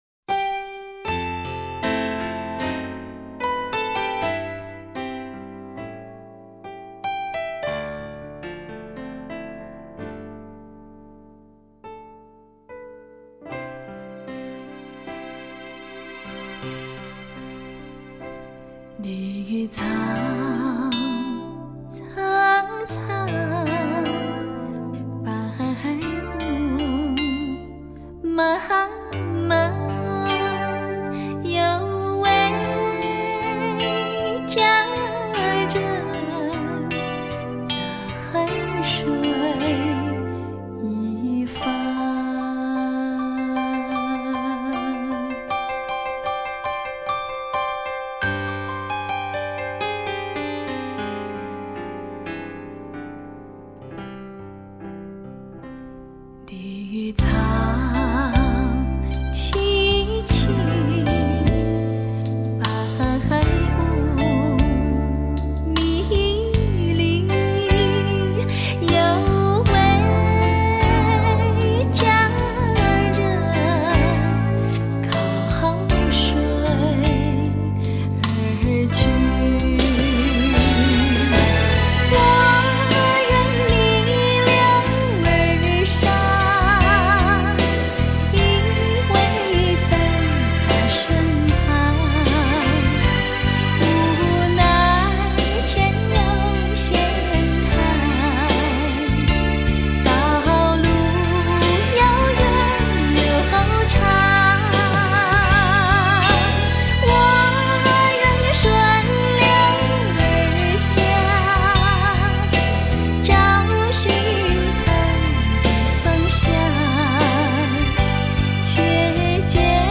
24BIT数码高频 原音毕露
超级柔情 经典贴心